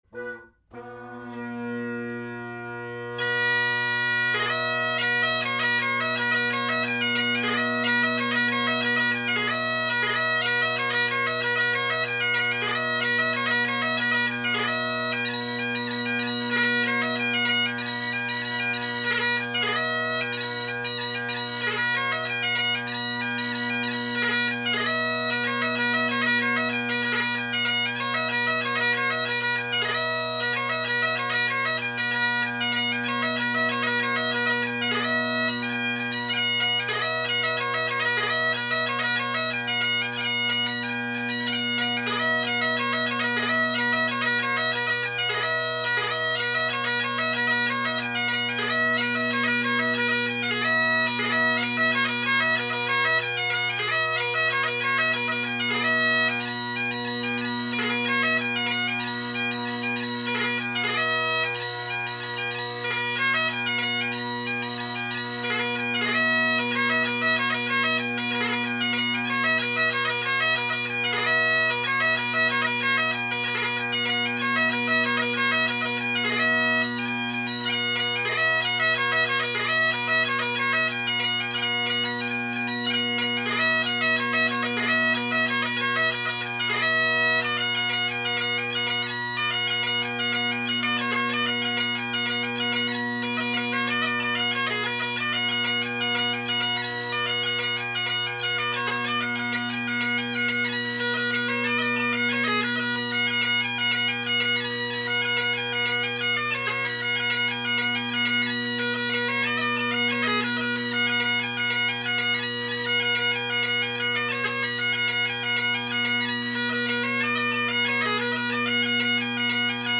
Great Highland Bagpipe Solo, Reviews
Jigs